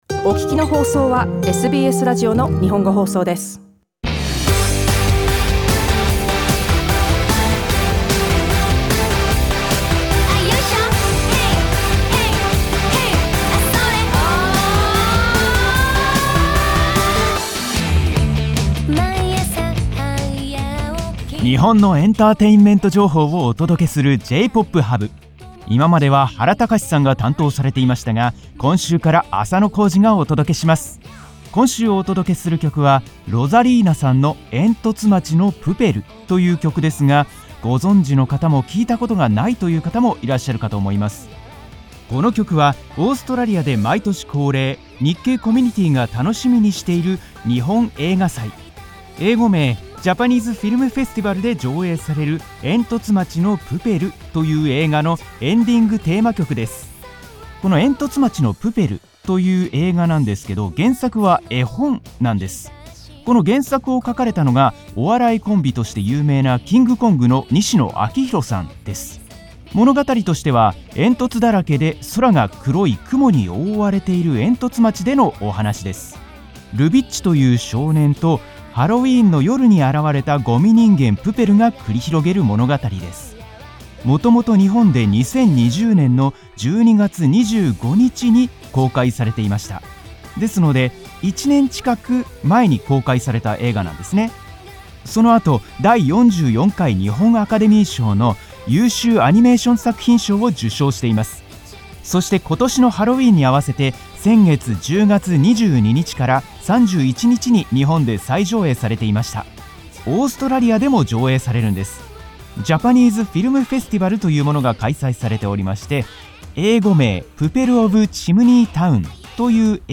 火曜日のラジオ番組でお届けしている日本の音楽・エンタメ情報コーナー「J-Pop Hub」。